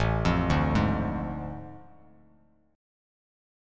AM7sus4#5 chord